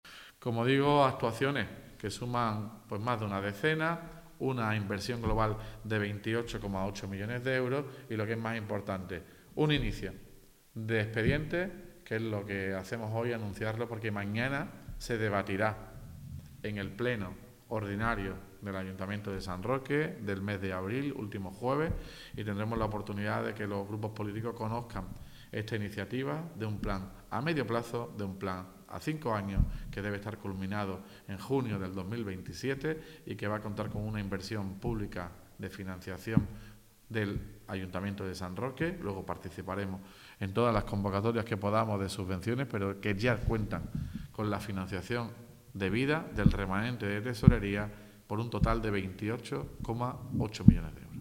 INVERSIONES_TOTAL_ALCALDE.mp3